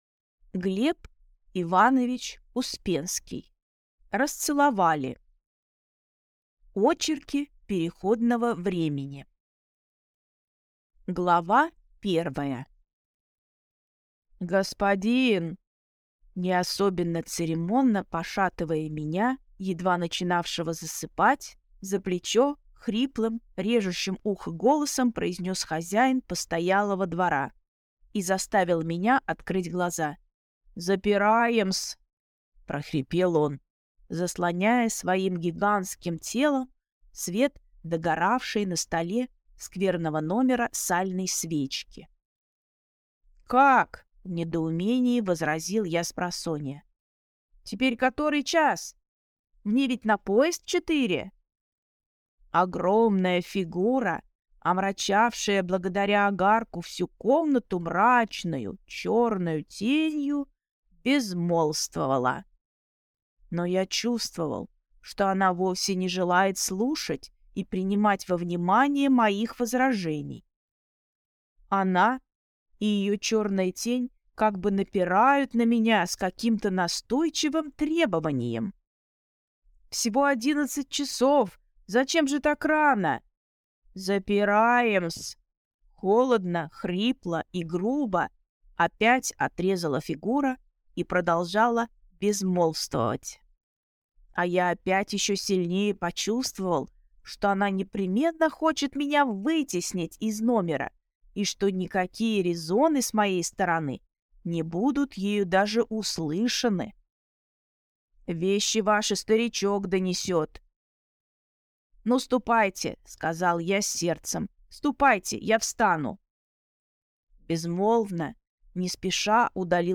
Аудиокнига «Расцеловали!»